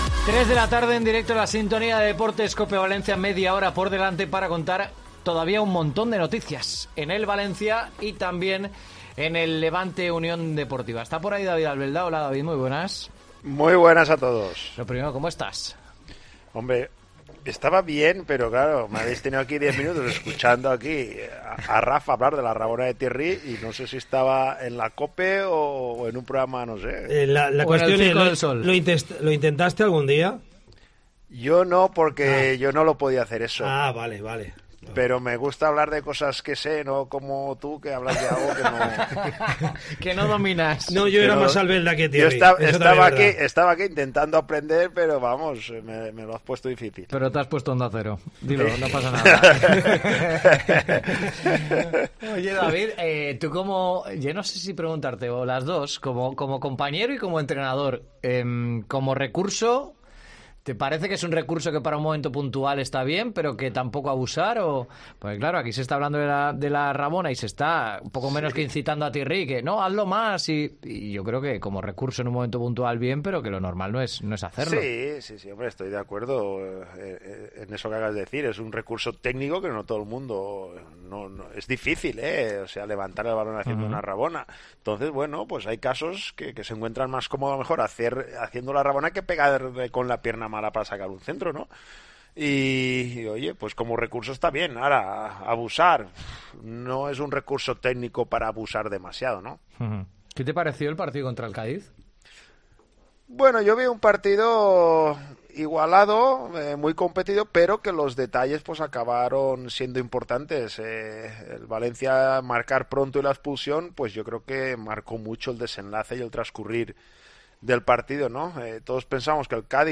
La leyenda del Valencia CF y comentarista COPE , David Albelda , se ha pasado por Deportes COPE Valencia para analizar la actualidad del Valencia CF tras diez jornadas del campeonato.